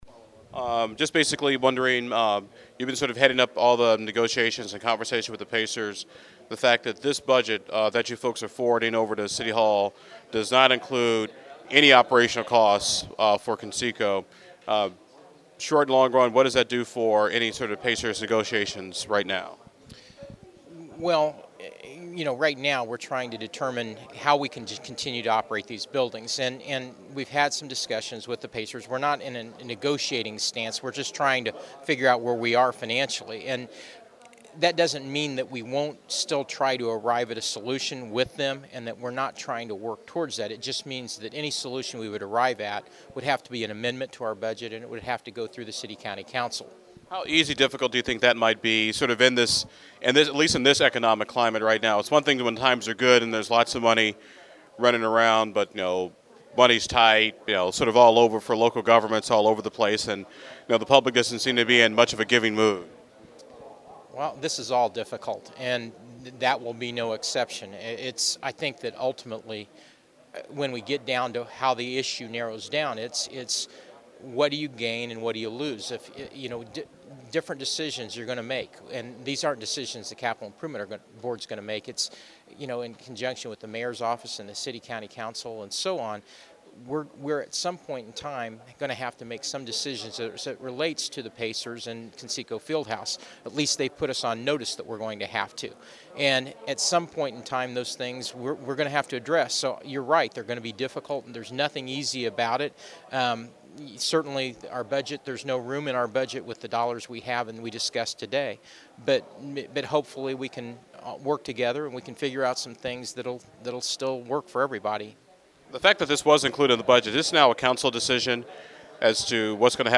Below is some audio from today’s meeting…